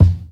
Kicks
KICK_BAD_MUTHA_2.wav